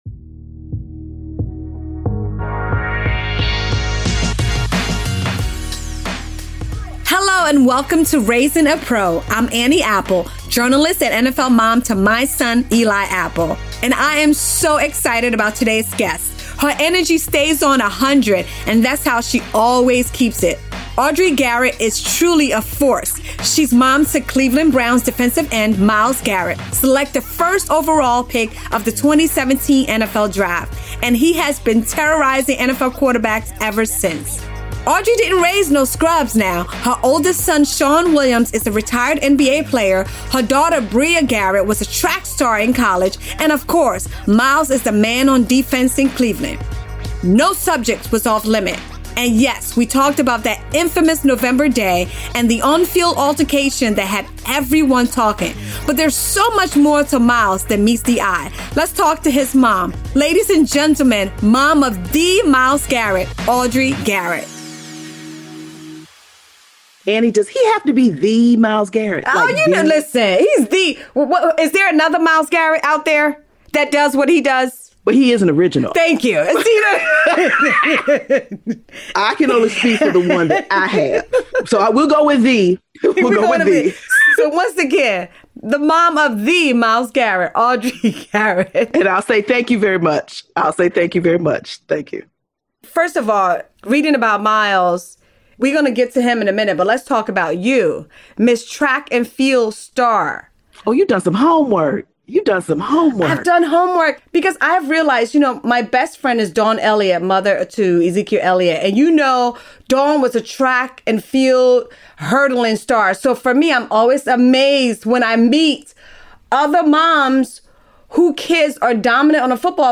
In this no holds barred conversation